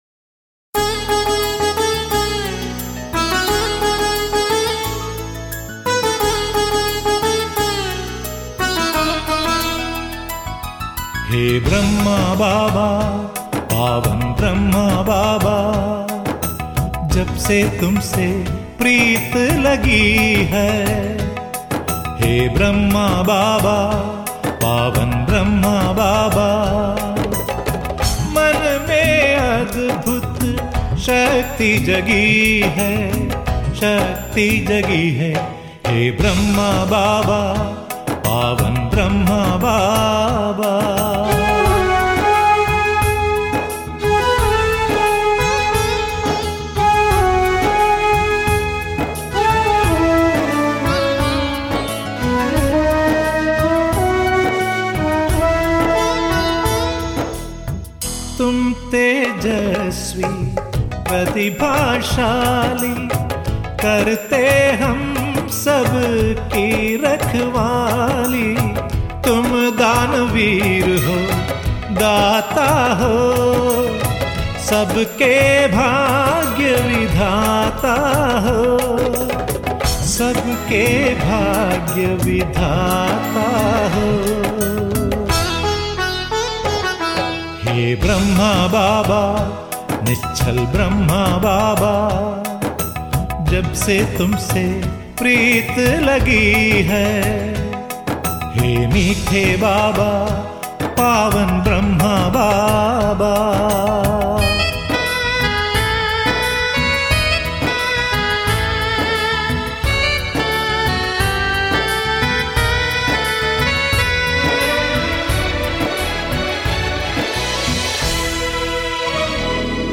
is a collection of Hindi devotional poetry, sung with music